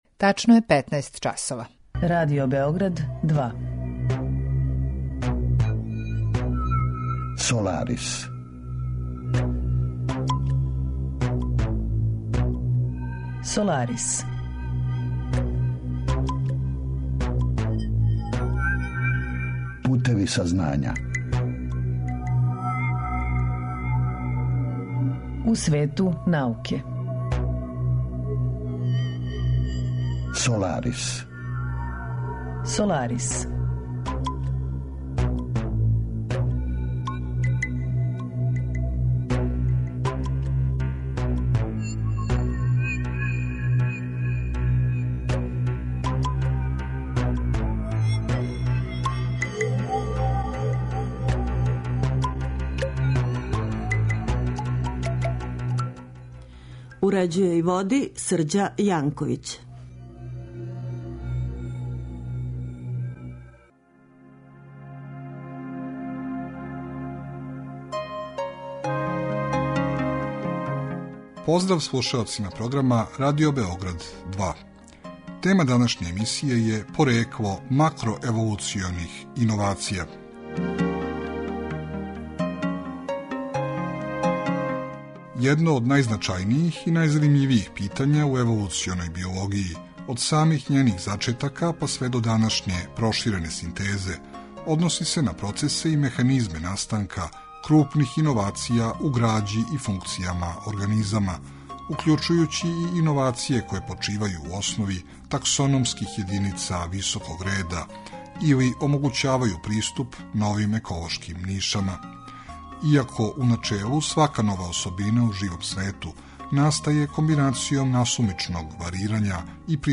Саговорник